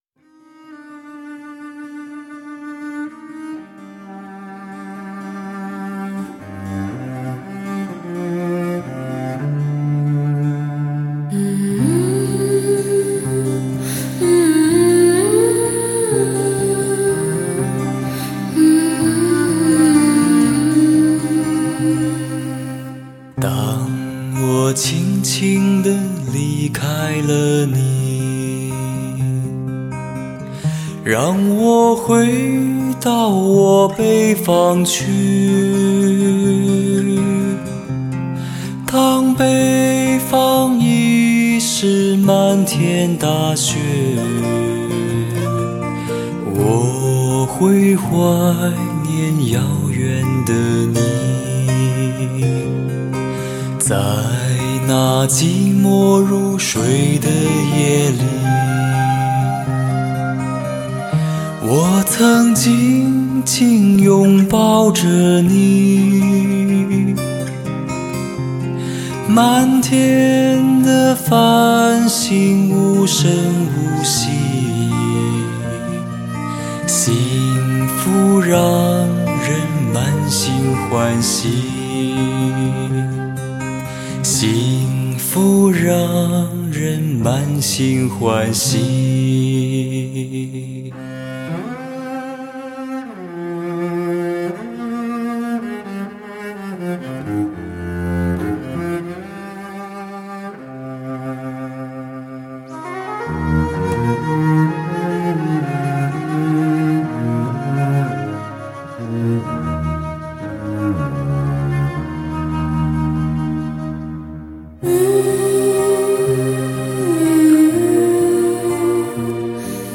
汽车发烧音乐HIFI典范专辑